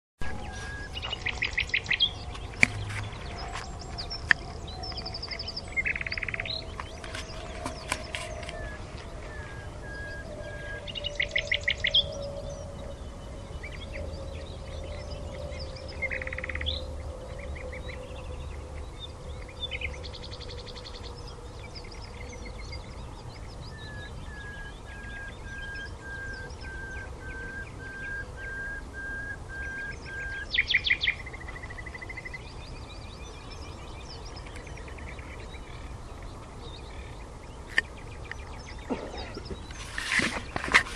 Geluid Nachtegaal 1